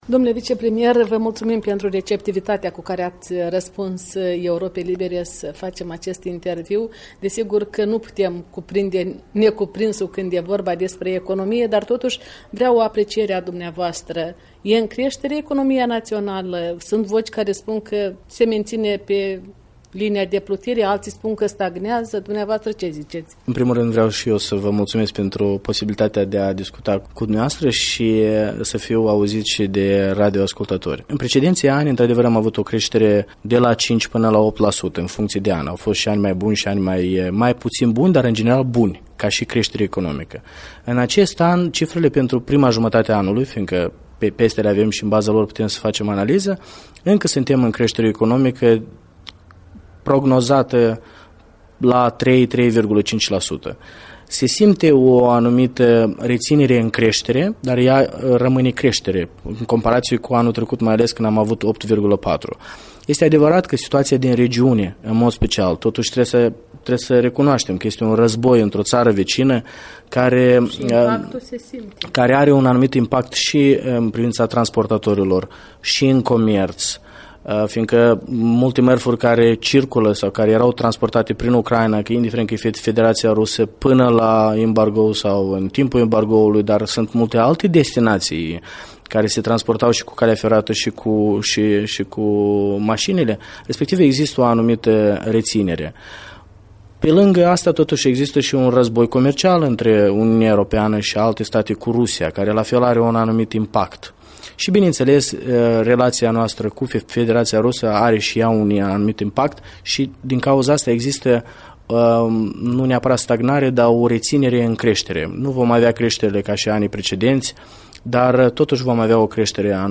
Interviu cu vicepremierul şi ministrul economiei Andrian Candu